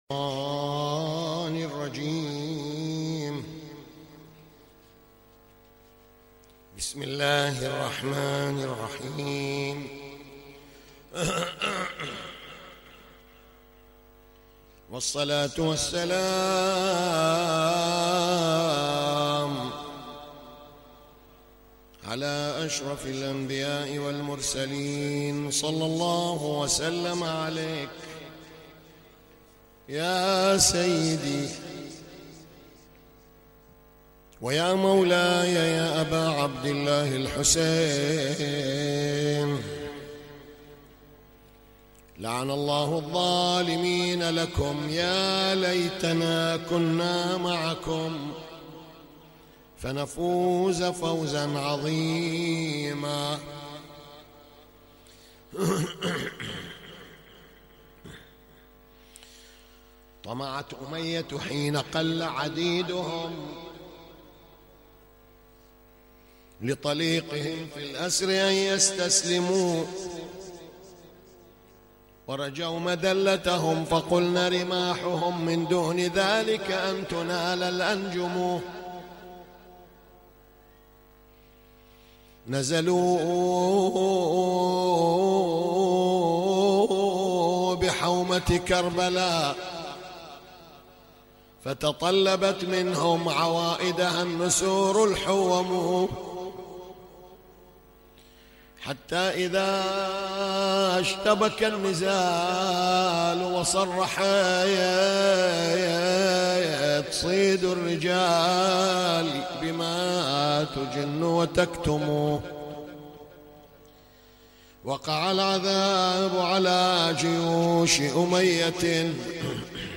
تغطية صوتية: يوم السابع من محرم الحرام 1439هـ